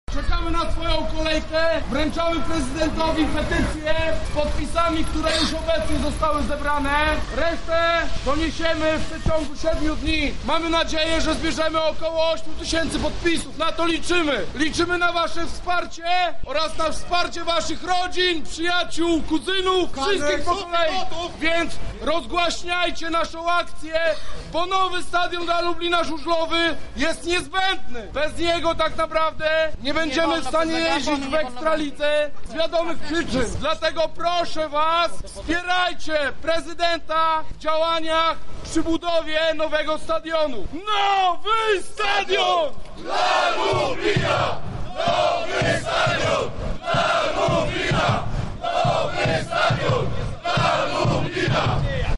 Podczas sesji poparcie dla powstania nowej infrastruktury sportowej na terenie obecnego klubu jeździeckiego wyrazili kibice Speed Car Motoru: